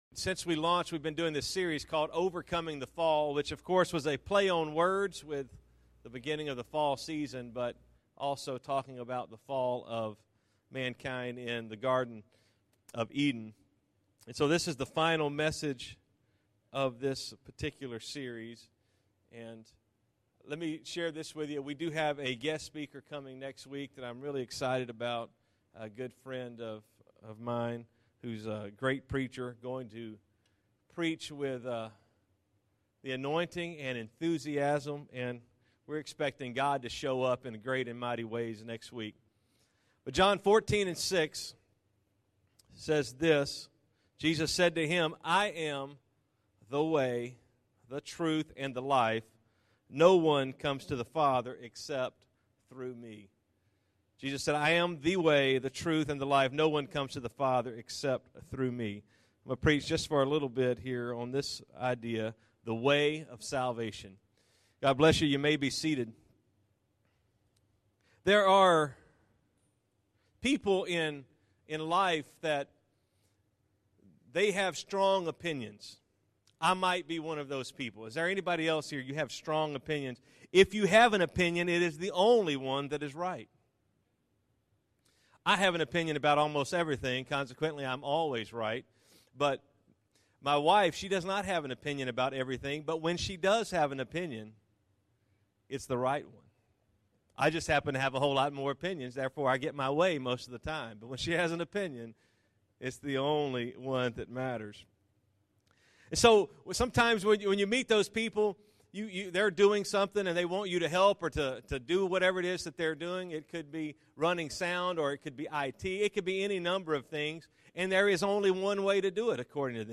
Overcoming The Fall Current Sermon